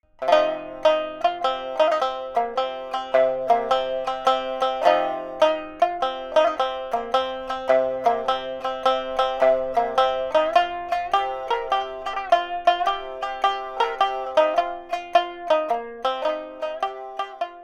Fröhliches Obon-Tanzlied für Shamisen
• Honchōshi Stimmung (C-F-C)
• Hazunde (Shuffle) Rhythmus